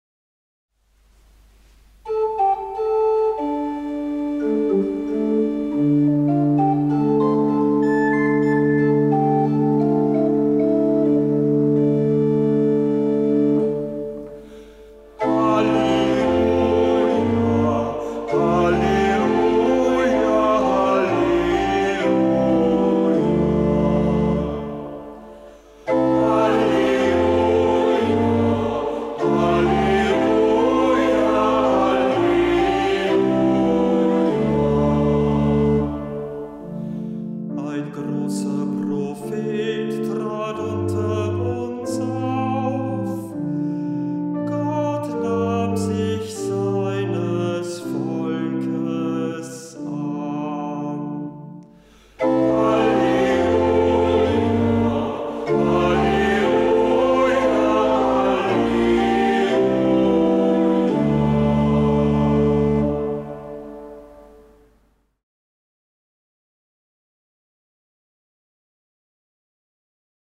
Ruf vor dem Evangelium - Juli 2024